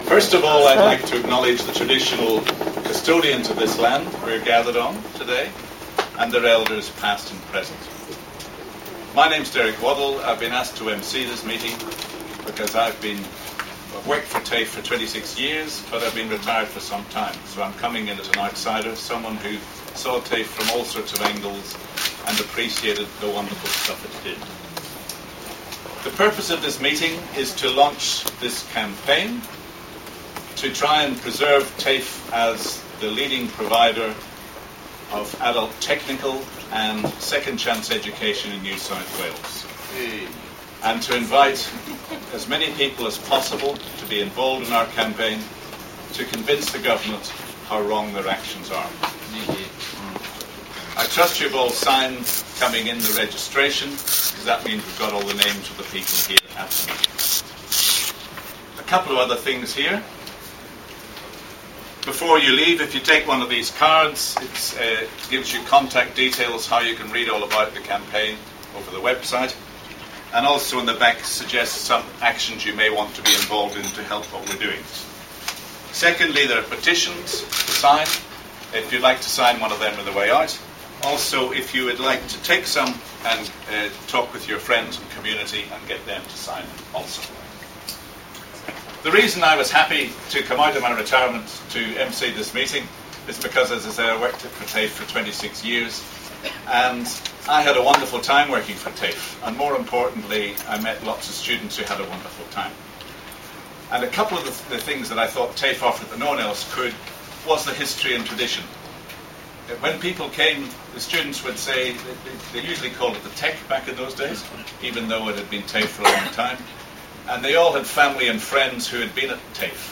On February 22 2013 the TAFE Community Alliance was formally launched in the heart of western Sydney at Western Sydney Community Forum, Parramatta.